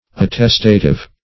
Attestative \At*test"a*tive\, a. Of the nature of attestation.